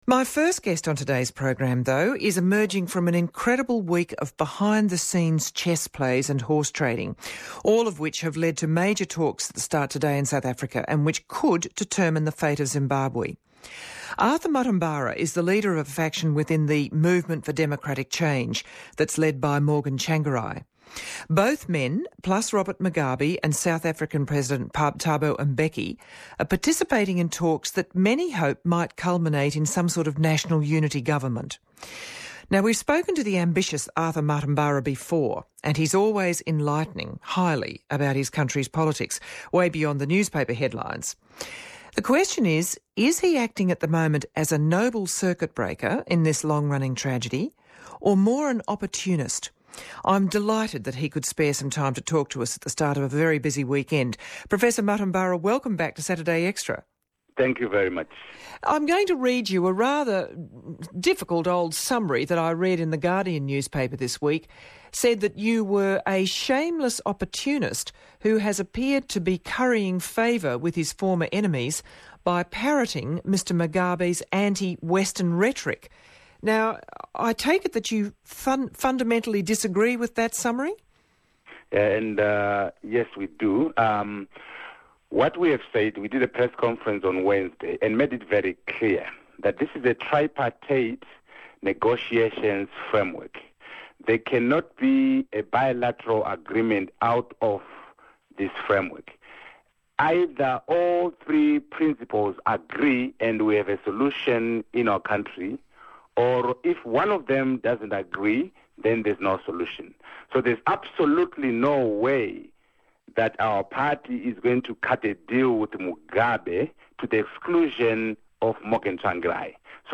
Arthur Mutambara interviewed by Geraldine Doogue ABC Radio National (Australia)